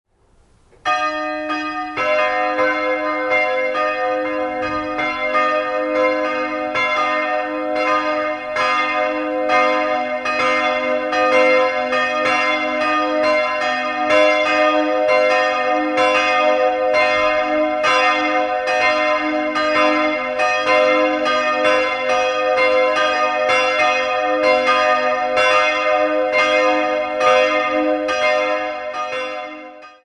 2-stimmiges Kleine-Terz-Geläute: h'-d''